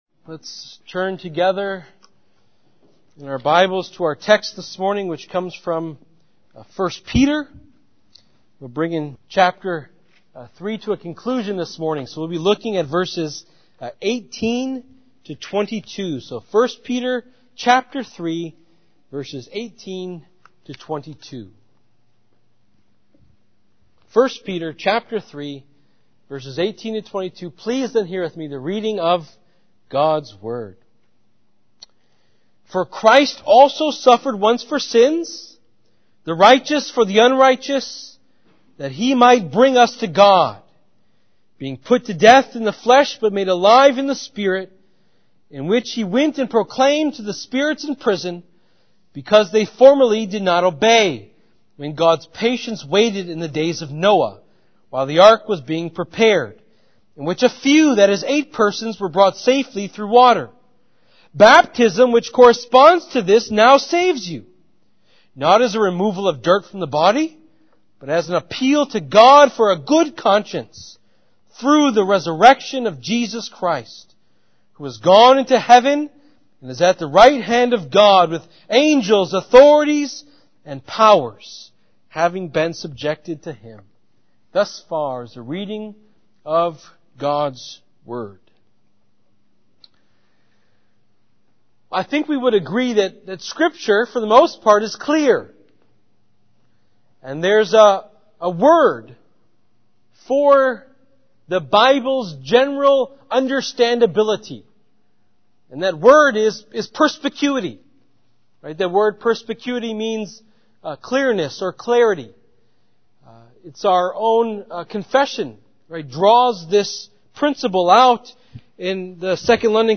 “Nothing Left To Fear” sermon (1 Peter 3:18-22)